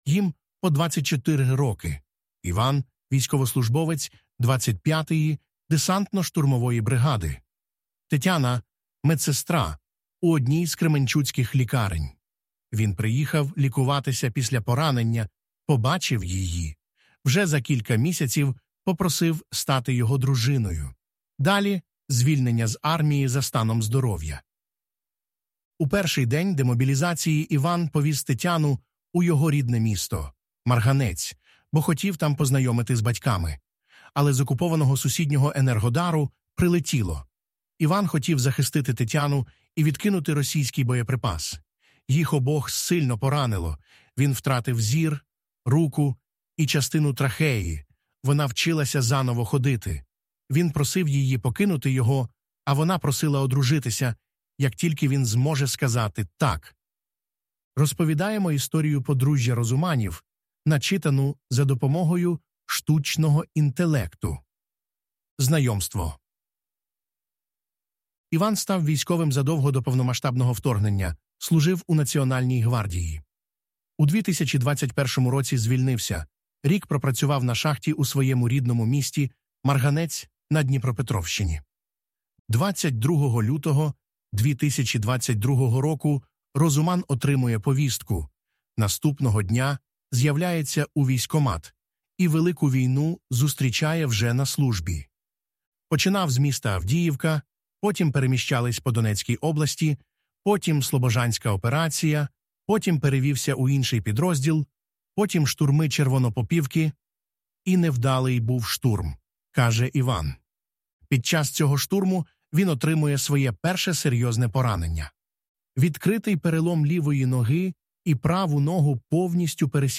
Ви можете прослухати цей текст, ми озвучили його за допомогою штучного інтелекту: